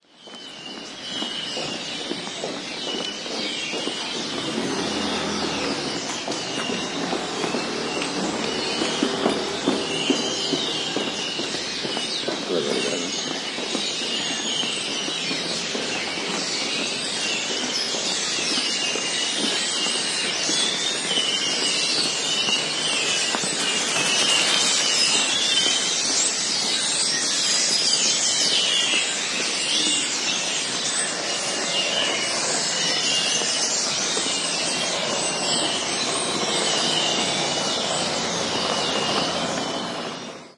鸟类 " 鸟类清晨 5
描述：麻雀的鸣叫。
城市的喧嚣。
Tag: 鸟鸣声 嗡嗡声 鸣叫 麻雀 隆隆声 早晨 麻雀 鸣叫 春天 清晨 城市 谜语